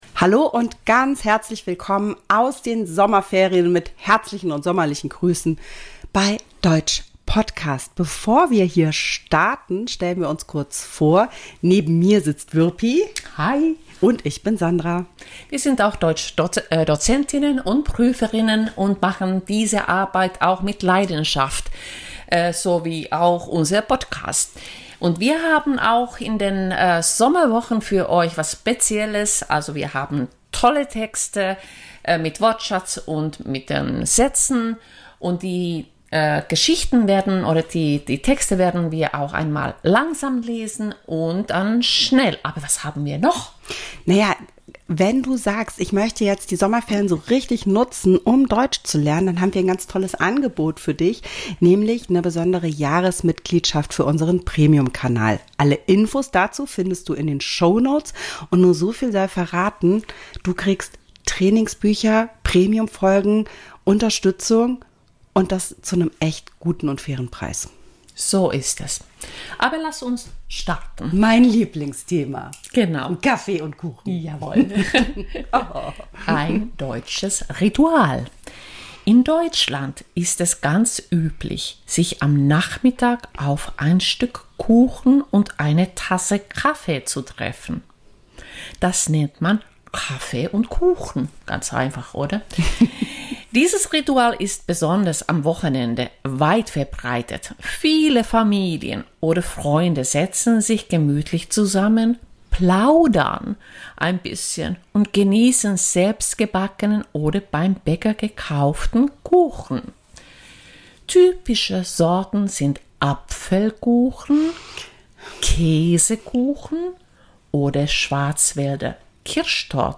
zwei zertifizierte Dozentinnen, Prüferinnen und unterrichten schon lange Deutsch als Fremd- und Zweitsprache. In jeder Woche präsentieren wir Euch eine neue Folge, in der wir über ein Thema sprechen und Euch an einigen Beispielen wichtige Inhalte der deutschen Grammatik vermitteln.